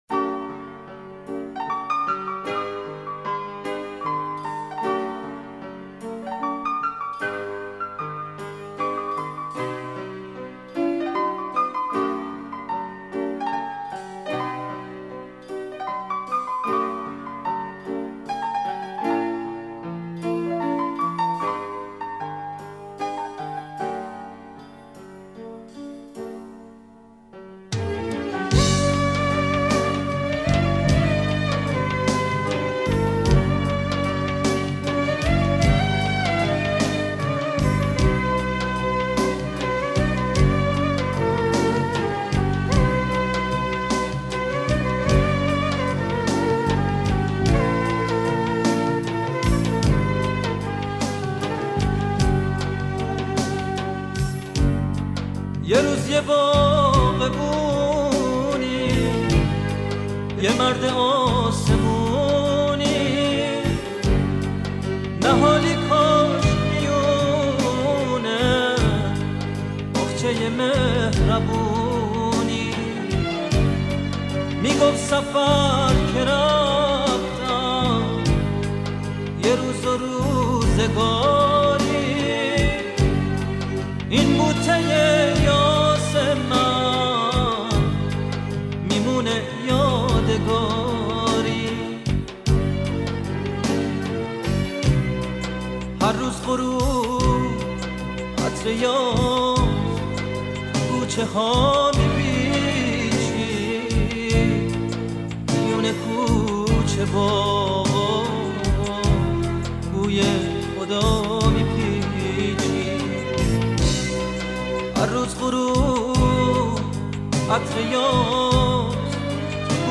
صدای گرم و دلنشین